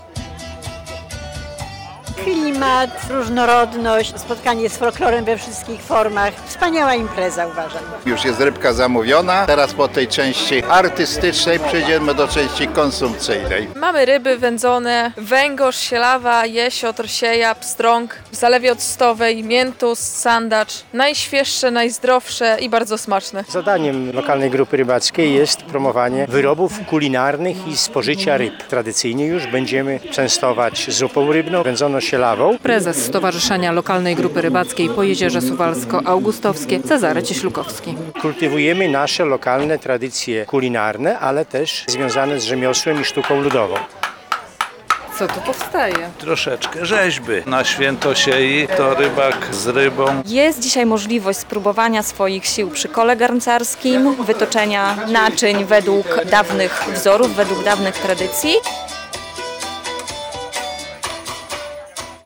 Wędzone sielawa i sieja, przetwory rybne, zupa z ryb słodkowodnych i morskich - to przysmaki, które serwowane są w sobotę (13.09) podczas tradycyjnego Święta Siei w Wigrach k. Suwałk.